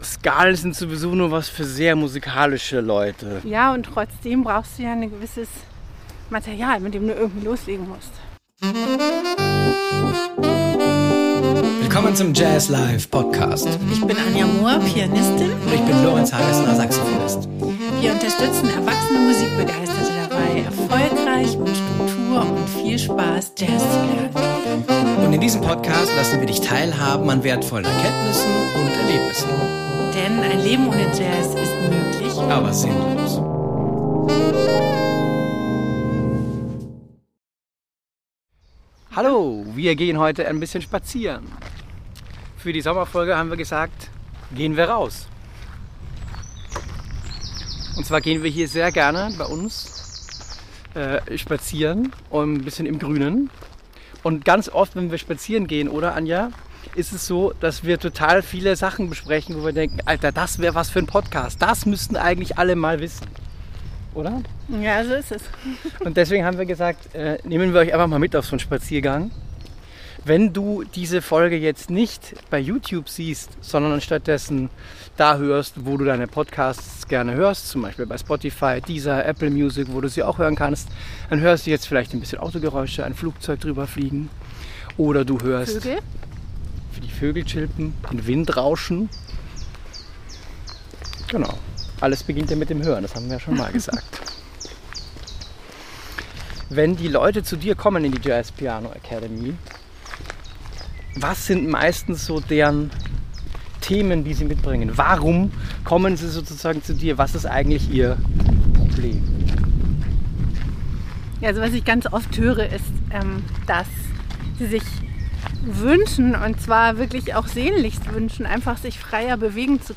Ein Gespräch über Jazz, Lernen, Vertrauen, innere Blockaden un...